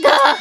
Worms speechbanks
ooff1.wav